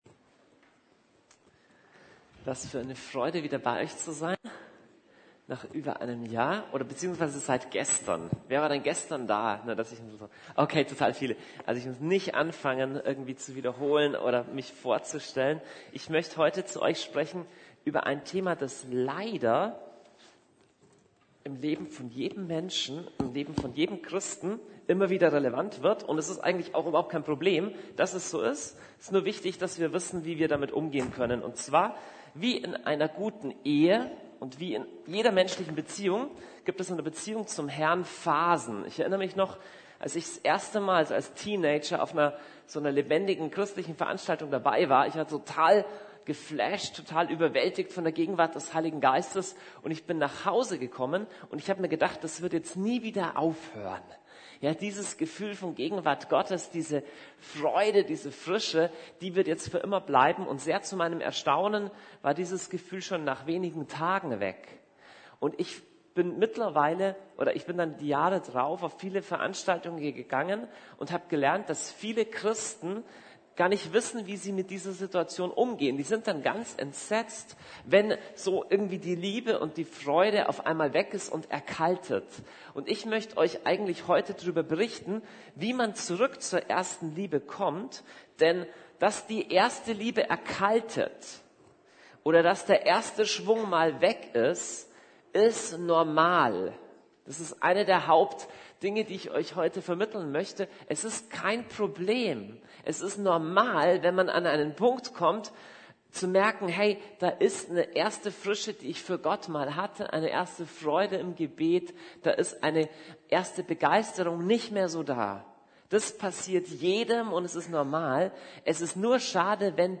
Zurück zur ersten Liebe ~ Predigten der LUKAS GEMEINDE Podcast